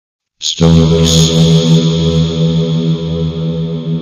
Stonks earrape Download